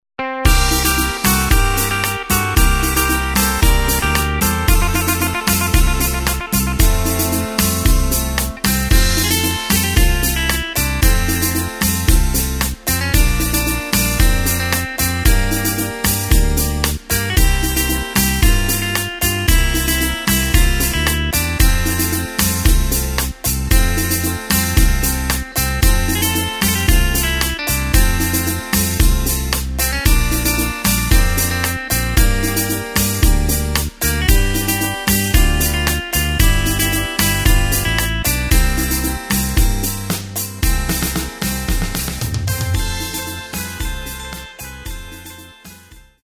Takt:          4/4
Tempo:         113.50
Tonart:            F
Schlager aus dem Jahr 1987!
Playback mp3 mit Lyrics